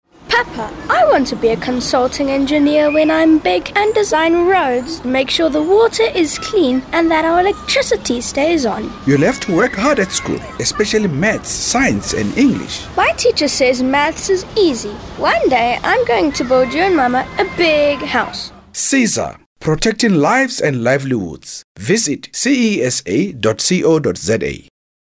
RADIO ADVERTS